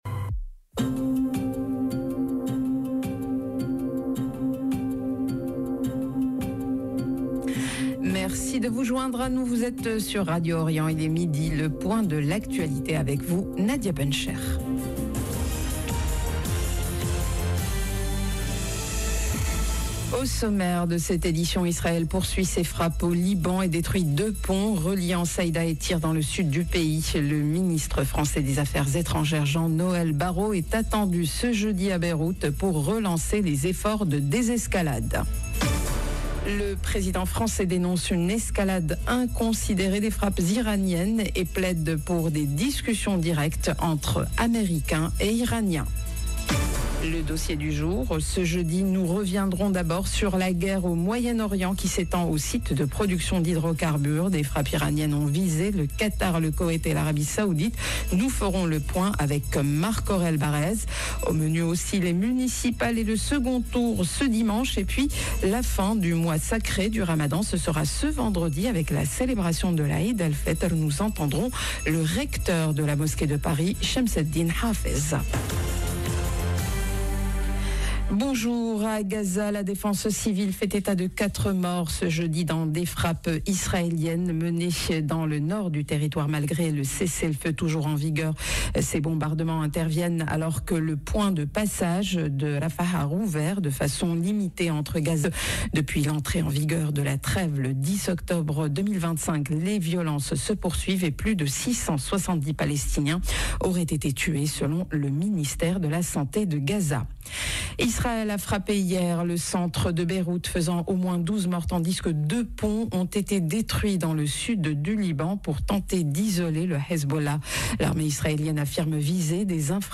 JOURNAL DE MIDI Au sommaire : Israël poursuit ses frappes au Liban et détruit 2 ponts reliant Saïda et Tyr dans le sud du pays.